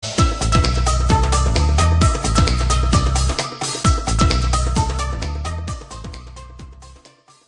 crash-edit.mp3